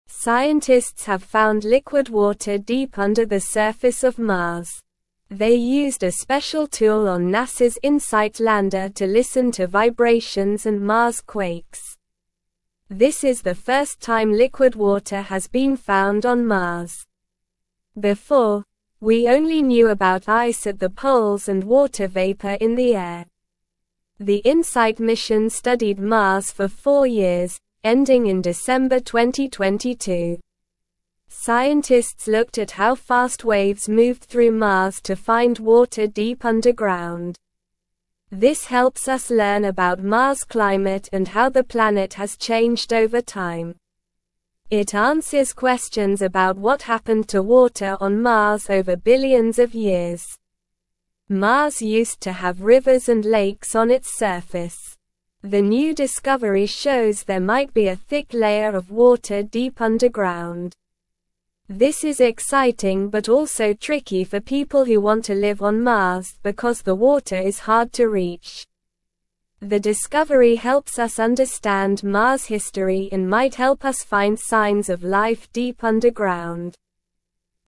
Slow
English-Newsroom-Lower-Intermediate-SLOW-Reading-Water-Found-on-Mars-Deep-Underground-Excites-Scientists.mp3